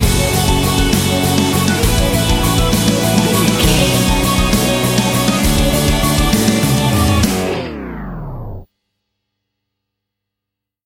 Aeolian/Minor
F#
drums
electric guitar
bass guitar
hard rock
aggressive
energetic
intense
nu metal
alternative metal